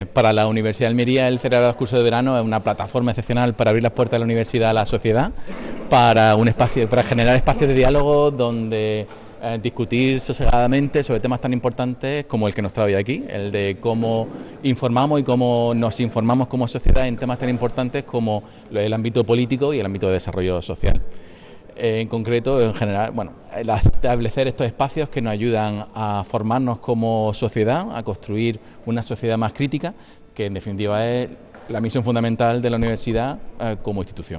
Académicos y profesionales de la información y la comunicación se dan cita durante tres días en el curso ‘Política, sociedad y comunicación’, que se está celebrando en la capilla del MUREC